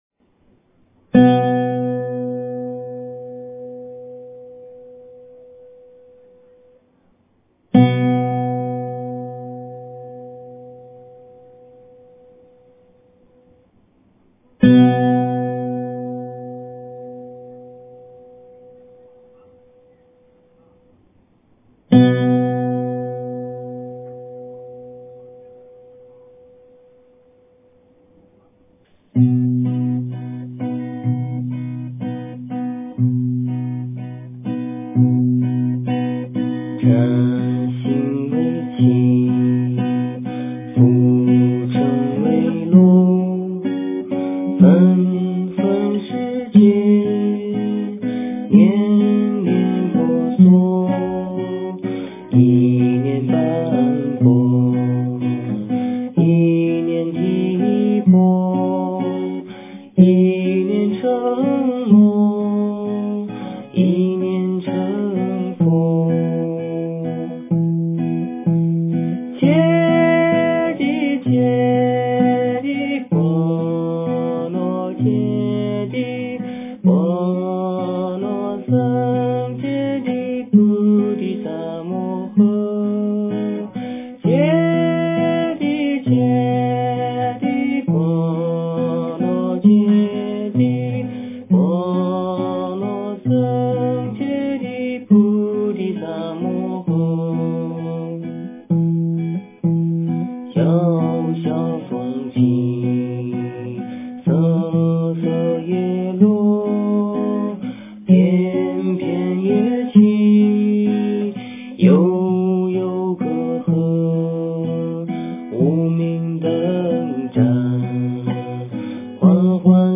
心经 诵经 心经--别来 点我： 标签: 佛音 诵经 佛教音乐 返回列表 上一篇： 大悲咒-车载 下一篇： 大悲咒-藏音 相关文章 文殊大威德金刚中阴解脱祈祷文--莫尔根 文殊大威德金刚中阴解脱祈祷文--莫尔根...